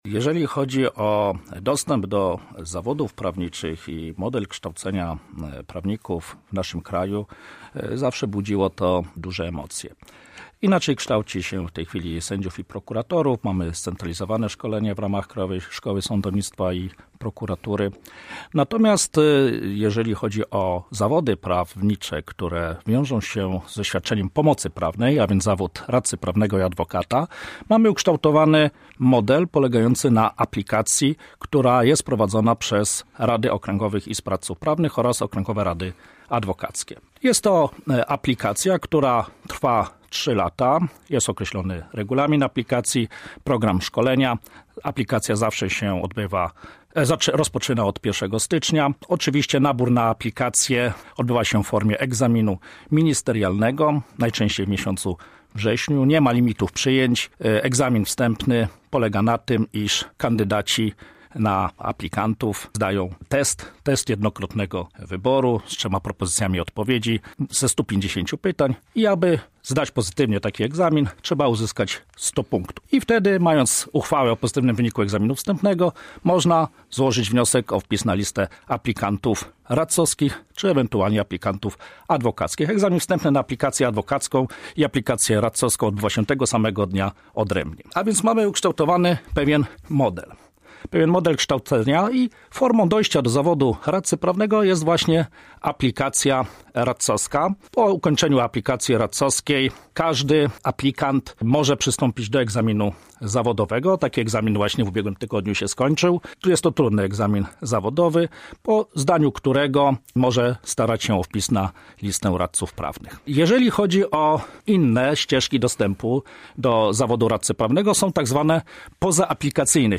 W studiu Akademickiego Radia Centrum na ten temat